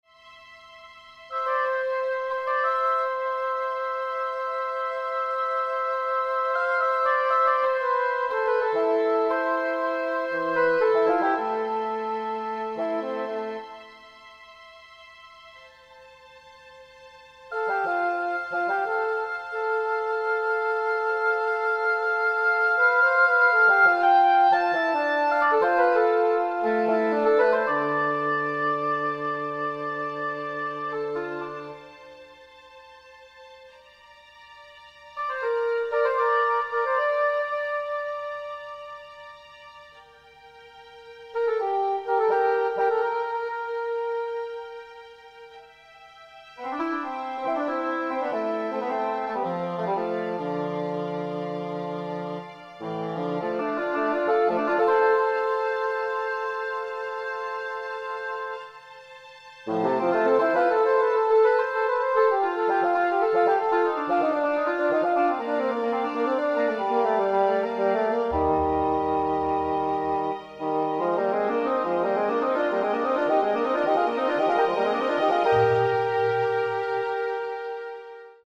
This video is a brief introduction to my latest composition, a double concerto for cor anglais and bassoon.
In this music I’ve given both instruments ample opportunity to express their unique characters and capabilities and explore the areas of their ranges that don’t overlap, while on the other hand emphasising their kinship and similarities in conversational passages where they engage in dialogue and imitation, such as this passage from the 1st movement:
What you hear here has been carefully crafted from sampled and synthesized sounds to be as realistic as possible, but synthesized music is never as good as the real thing.